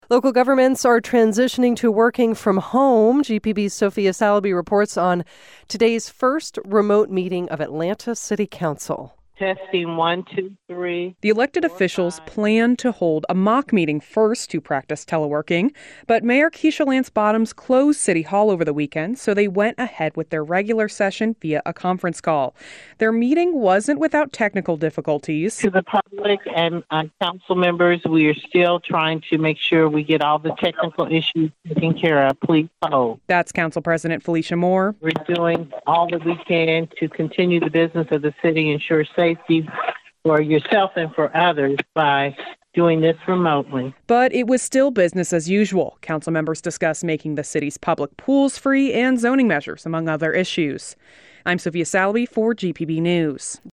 Council President Felicia Moore presided over the council remotely.
Instead, Atlanta City Council went ahead with their regular session via a conference call.
The meeting wasn’t without technical difficulties as council members and city staff made sure they were connecting to the call and got used to electronically voting.